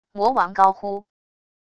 魔王高呼wav音频